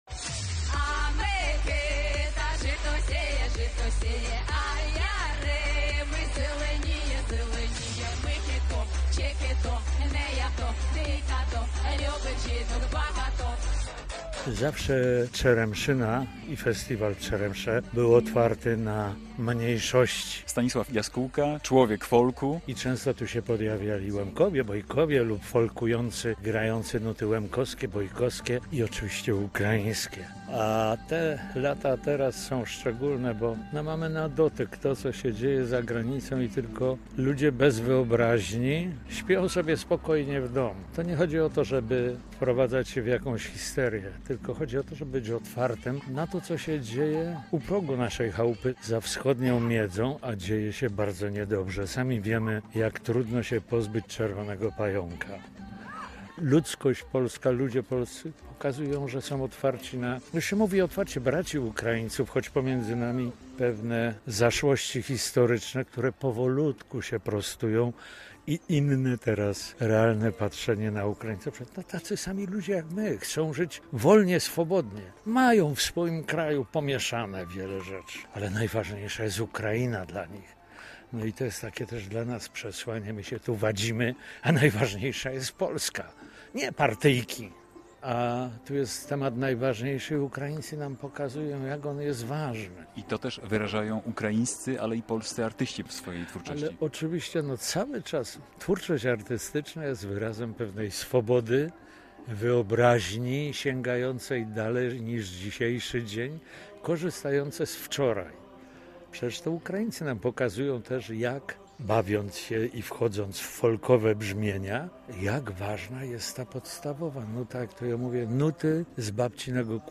Festiwal Czeremcha Wielu Kultur i Narodów cz. 1 - relacja